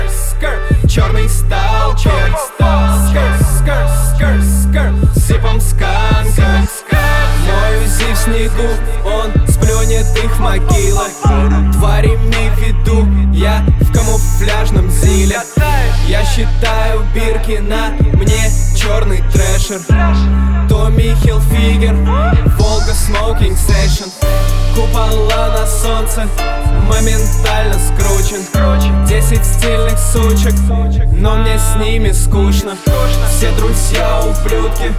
Жанр: Рэп и хип-хоп / Альтернатива / Русские
# Alternative Rap